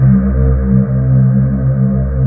Index of /90_sSampleCDs/USB Soundscan vol.28 - Choir Acoustic & Synth [AKAI] 1CD/Partition D/01-OUAHOUAH